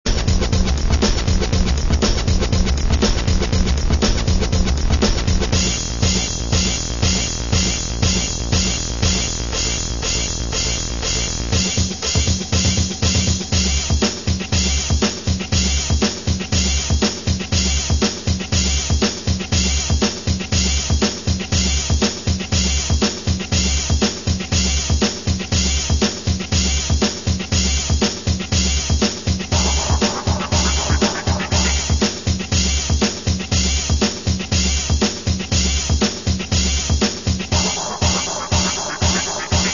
Segunda maqueta con tonos electro-rock bailables.